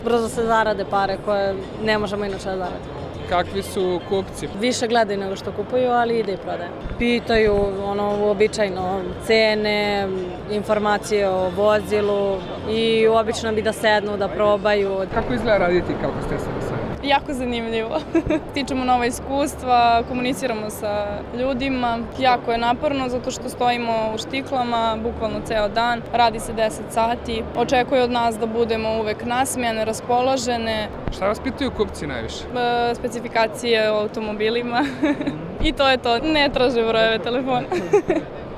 Neke od tih devojaka rekle su RSE da posao nije lak, ali da je isplativ.
Hostese o Sajmu automobila